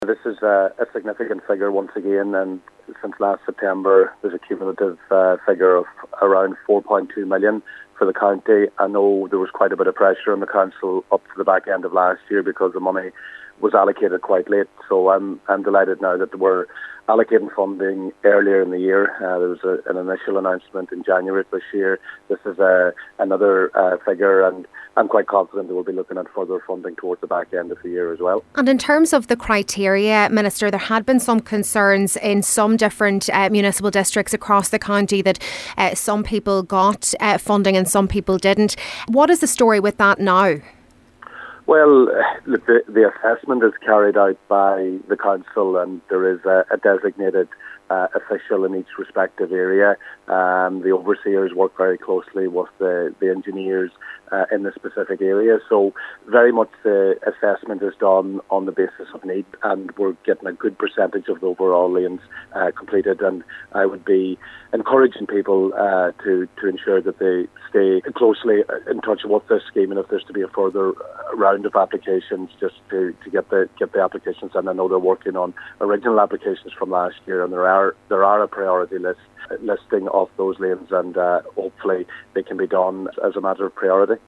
Donegal Minister Joe McHugh says it’s important for those who missed out on the latest round to keep in touch with the scheme as he envisages another tranche of funding later in the year: